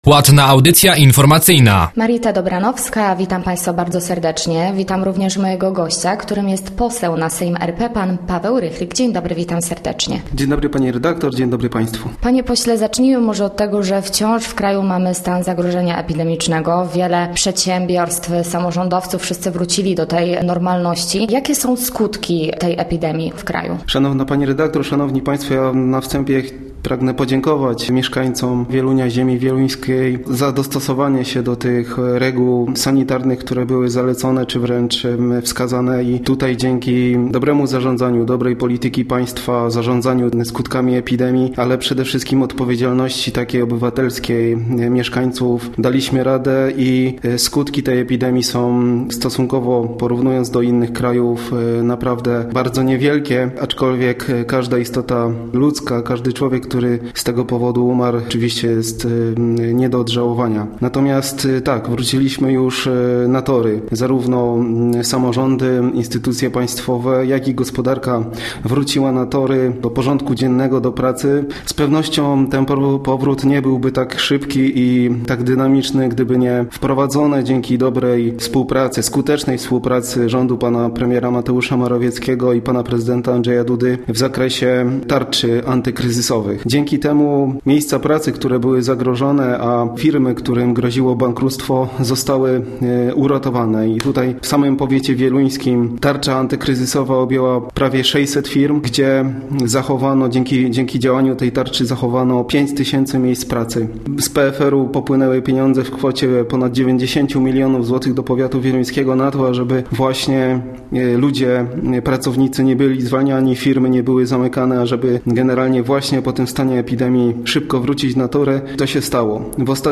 Gościem Radia ZW był Paweł Rychlik, poseł na Sejm RP